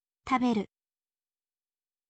taberu